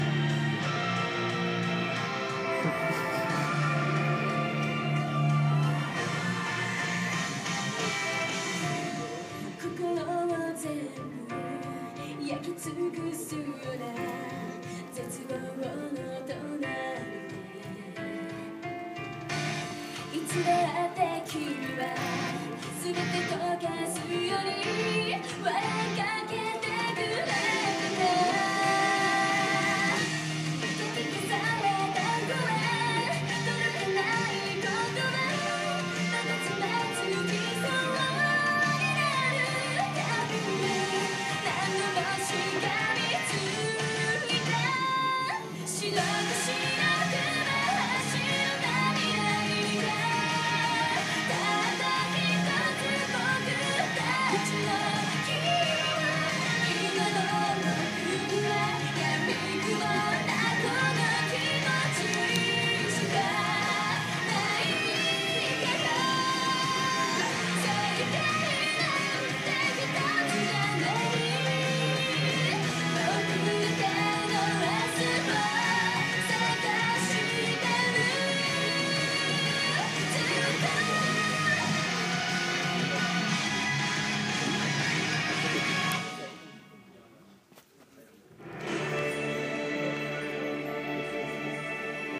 tv size
I don't like it at all, and her voice is annoying.